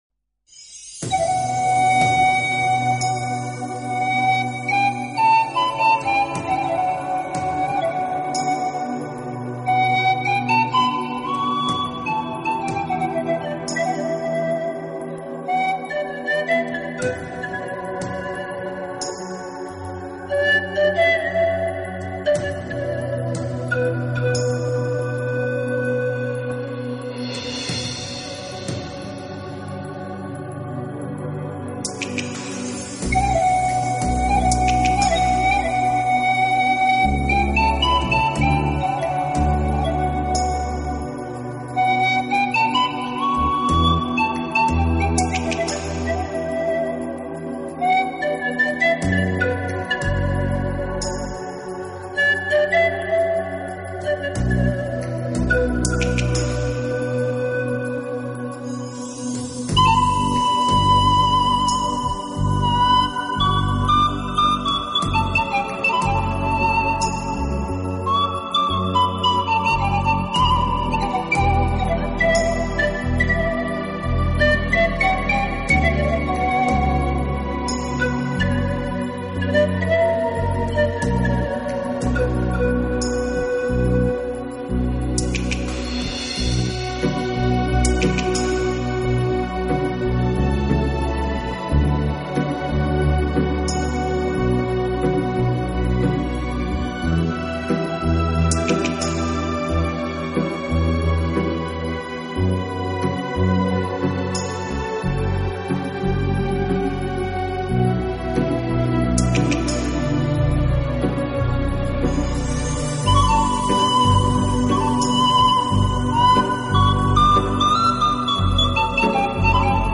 类型: Instrumental
优雅，像一个透蓝深遂的梦境。